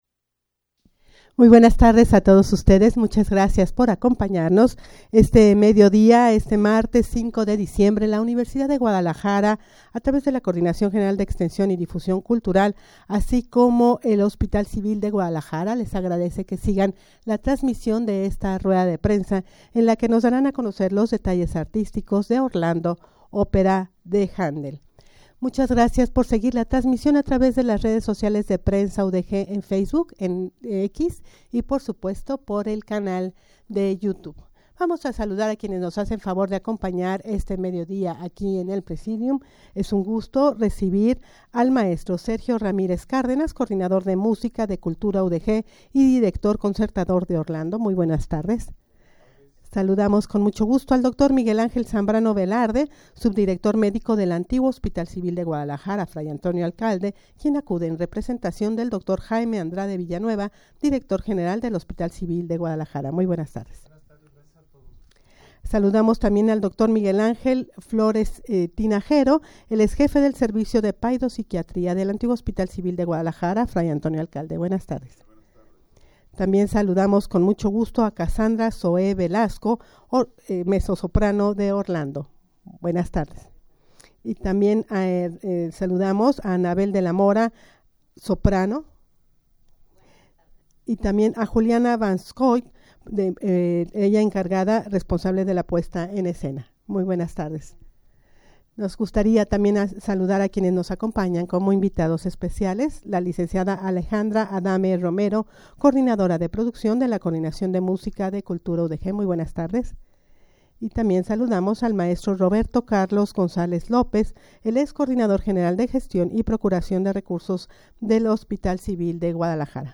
rueda-de-prensa-presentacion-de-orlando-opera-de-handel.mp3